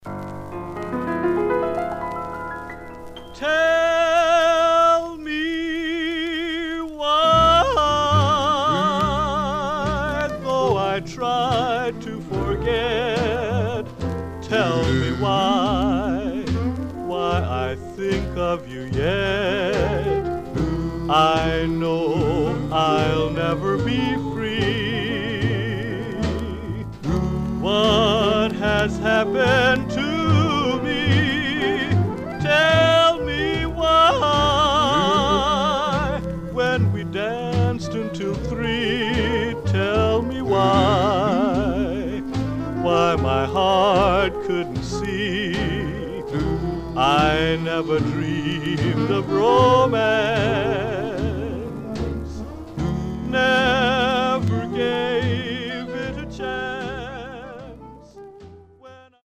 Mono
Male Black Group With Company Sleeve Condition